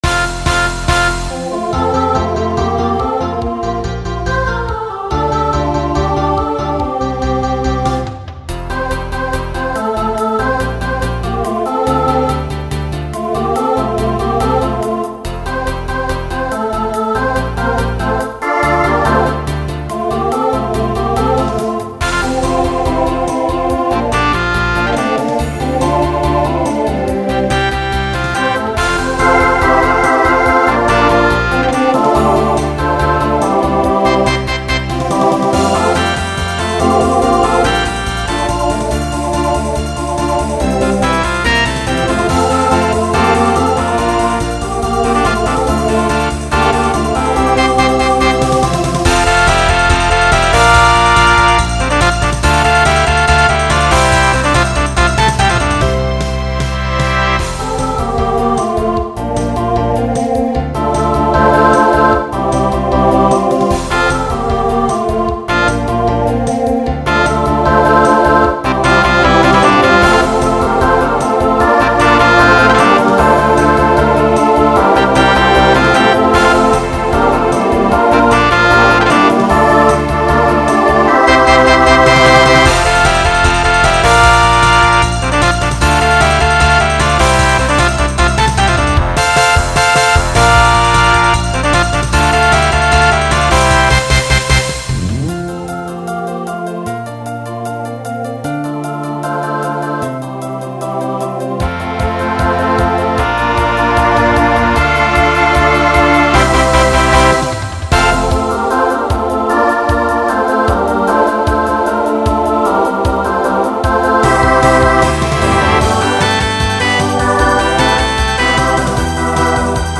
Includes custom bow.
Genre Pop/Dance , Rock
Voicing SATB